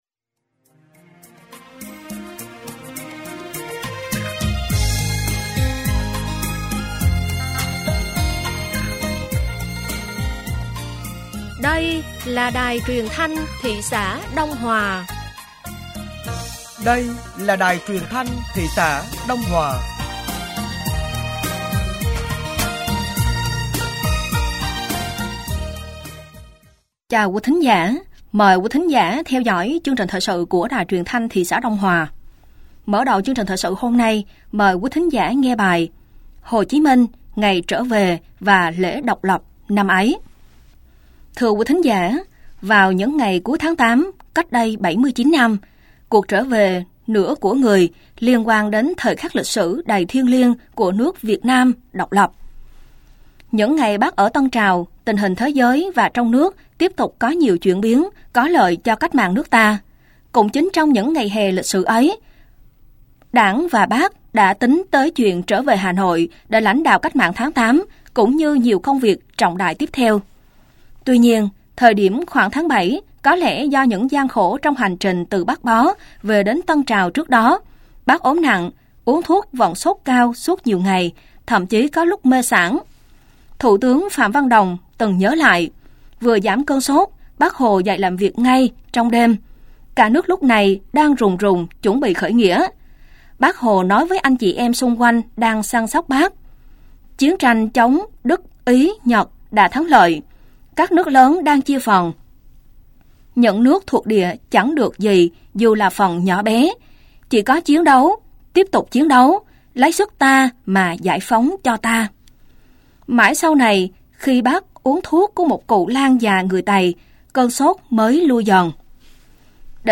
Thời sự tối ngày 01 và sáng ngày 02 tháng 9 năm 2024